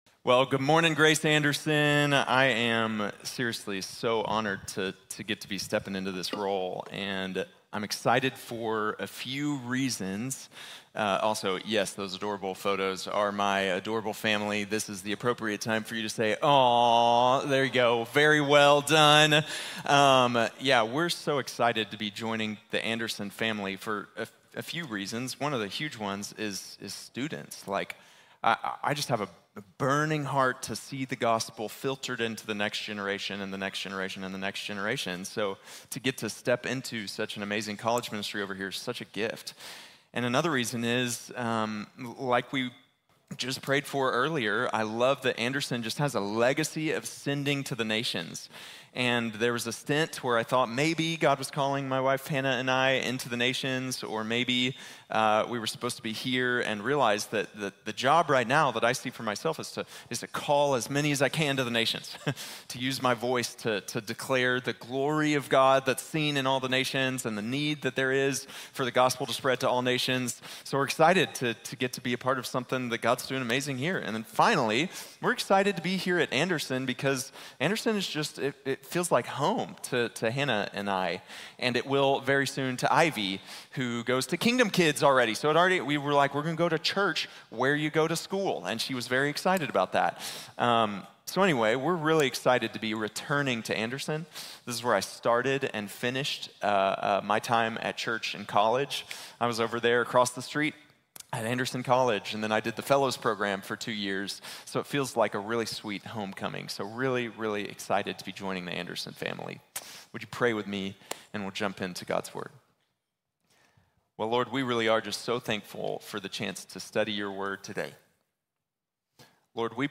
Motivation for Ministry | Sermon | Grace Bible Church